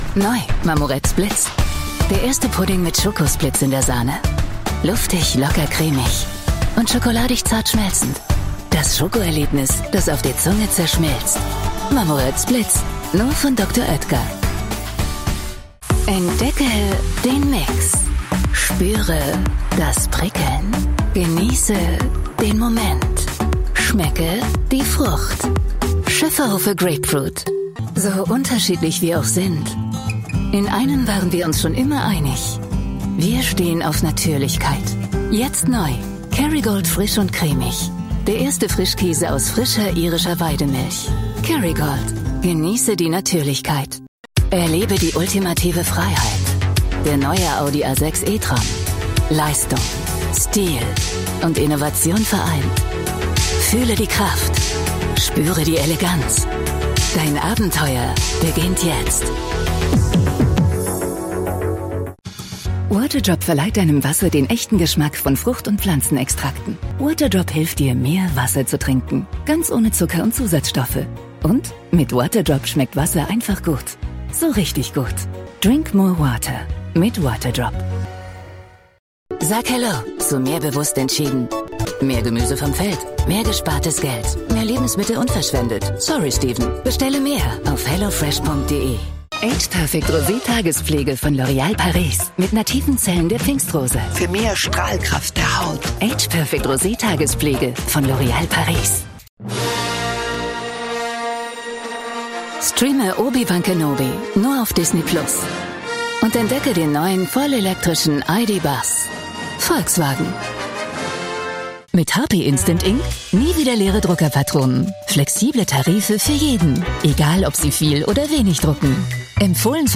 Eine Stimme, die Sie genießen werden: - warm, markant, angenehm, - sympathisch, seriös und verbindlich, - wohltuend, charmant und wandelbar.
Sprechprobe: Werbung (Muttersprache):